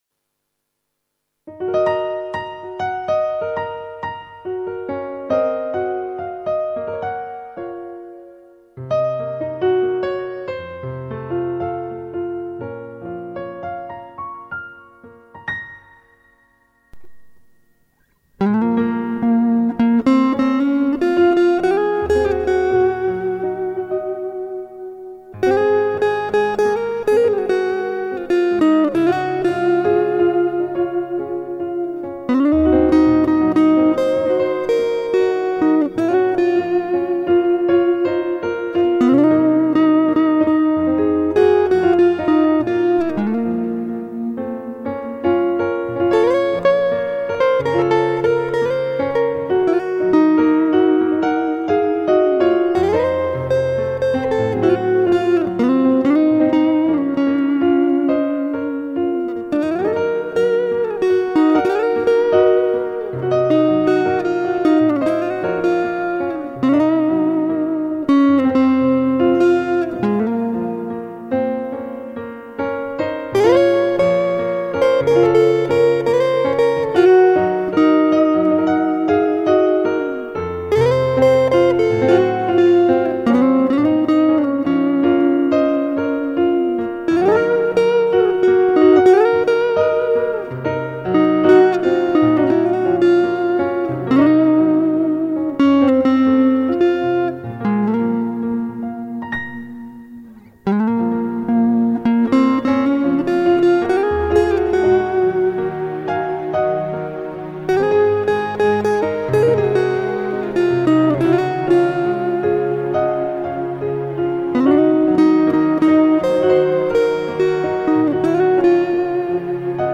Спасибо, конечно, но это не песня а мелодия...и то что это Черемшина понятно....я интересуюсь кто исполнитель этой мелодии?
nevidomij---(instrumental)-cheremshina.mp3